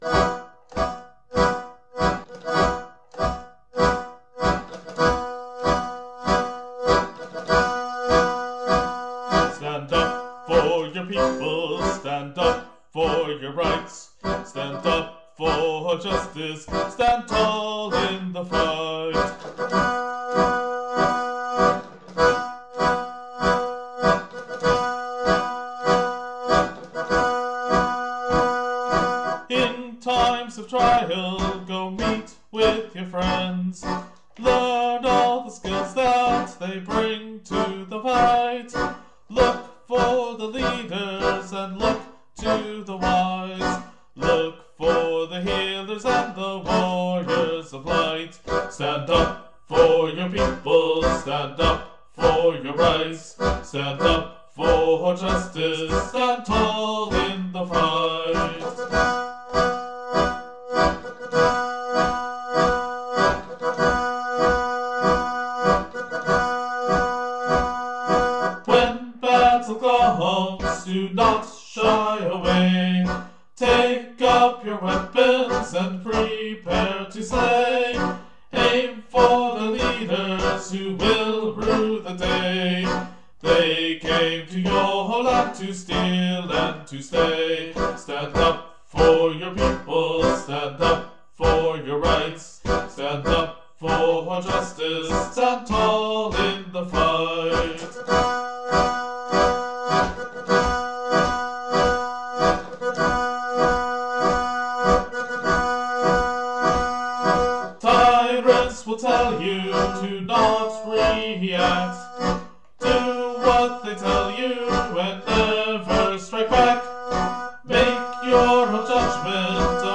Musically, this aimed for a march, which has military associations going back to antiquity. Simple, disciplined, and powerful is the idea here.
The constant stomping feet were added specifically to emphasize this point, along with the growing chorus of support for the single verse that started this out.